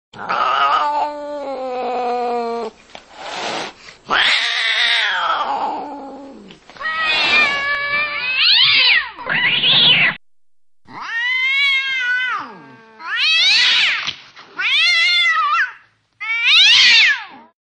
Pelea de gatos V. 1: efectos de sonido gratis
Desde maullidos agudos y gruñidos amenazantes hasta bufidos y arañazos, esta selección de sonidos te transportará a una escena de batalla épica entre gatos.
Cada efecto de sonido ha sido grabado con alta calidad, capturando la esencia de una pelea de gatos con realismo y detalle. Los maullidos y gruñidos varían en tono e intensidad, desde los suaves y cautelosos hasta los fuertes y agresivos.
Los bufidos y arañazos añaden una capa de realismo, creando una atmósfera tensa y emocionante.
Pelea de gatos V1.mp3